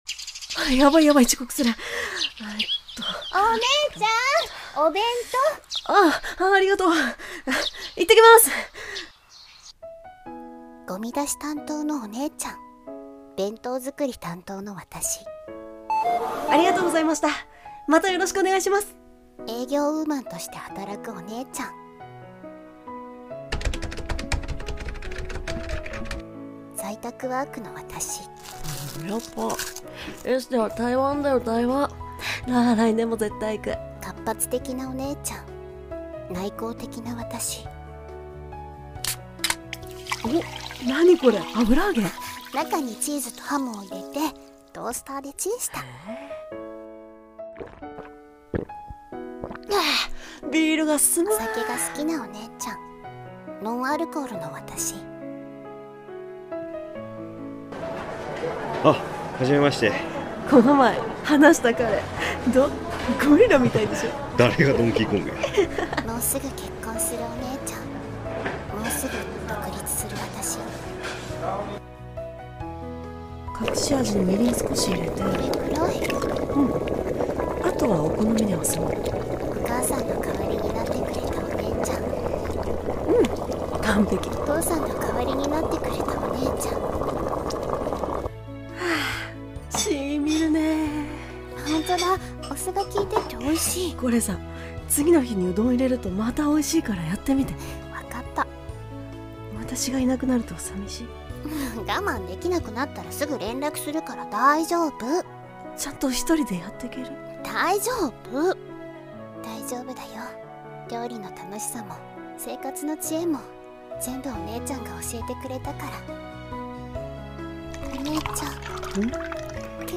【CM風 2人声劇】料理 〜姉妹編〜 (性別不問)